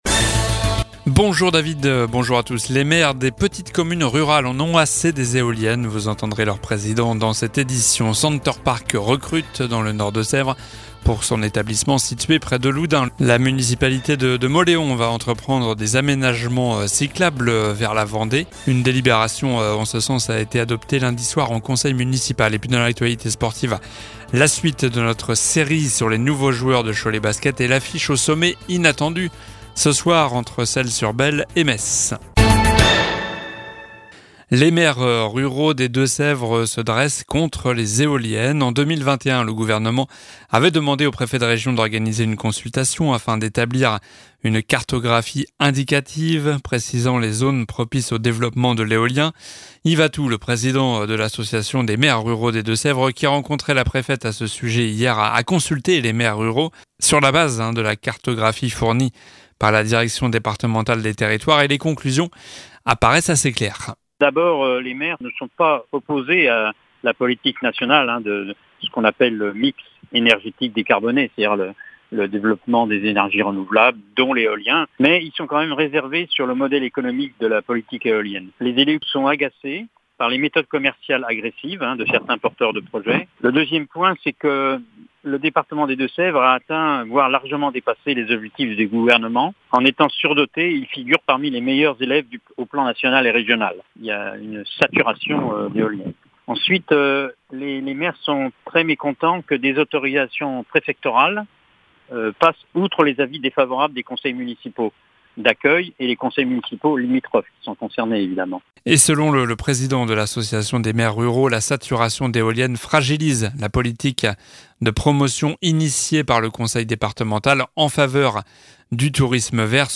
Journal du mercredi 21 septembre (midi)